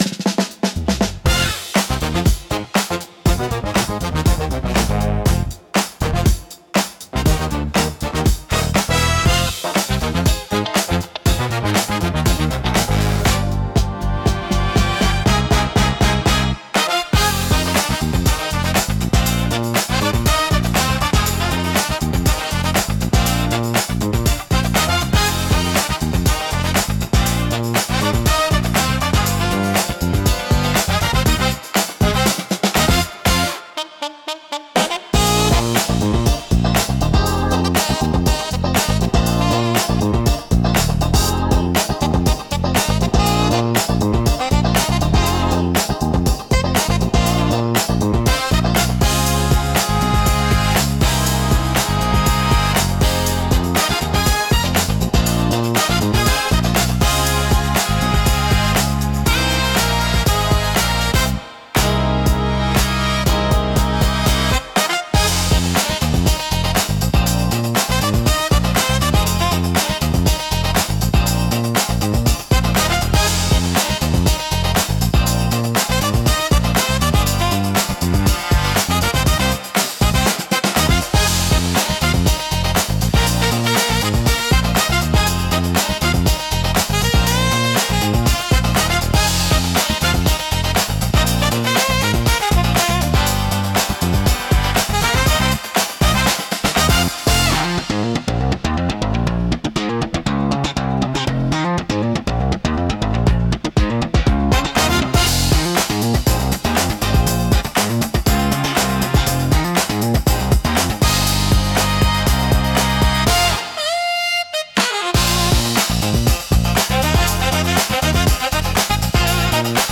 躍動感と活気に満ちたジャンルです。